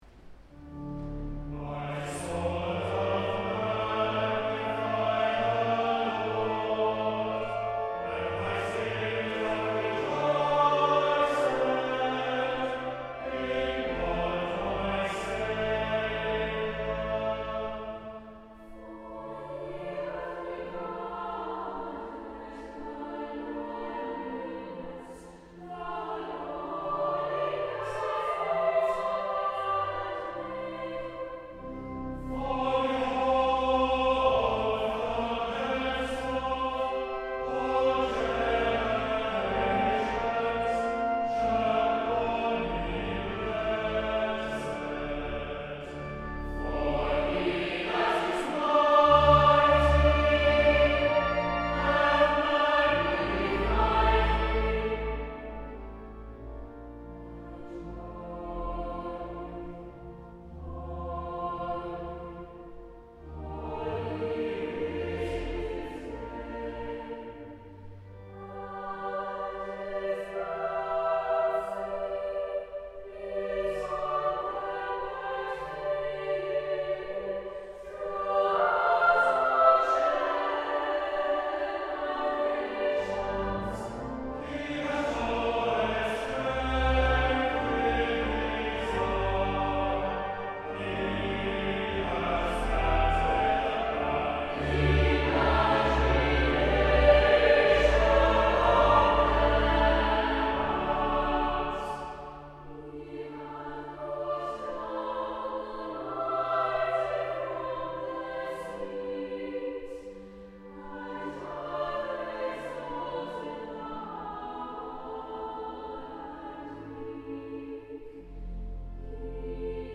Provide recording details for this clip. Live Recordings The recordings are not professional quality, and were taken with a single microphone during a live performance.